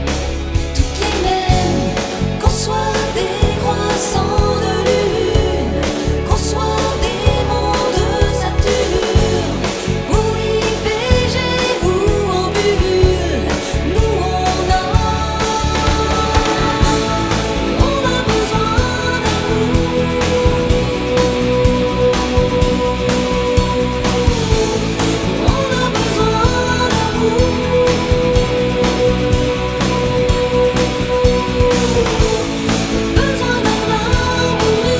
Жанр: Поп музыка / Танцевальные / Электроника